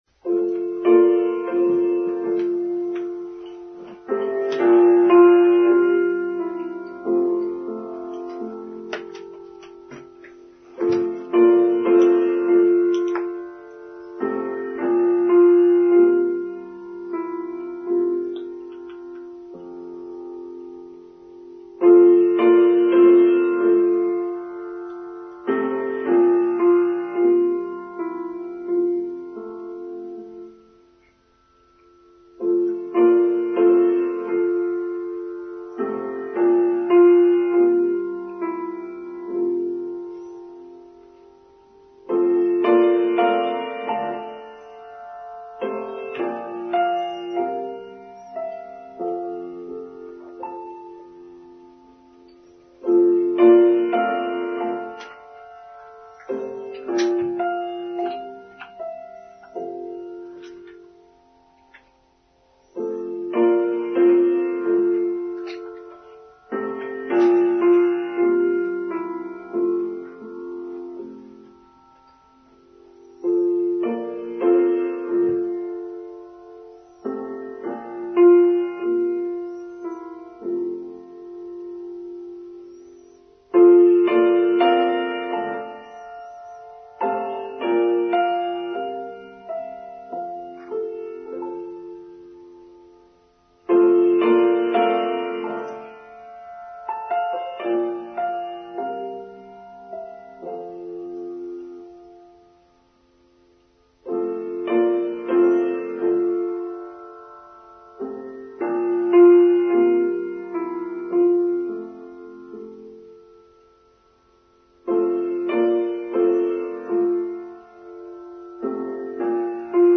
The Ascent of Man: Online Service for Sunday 7th May 2023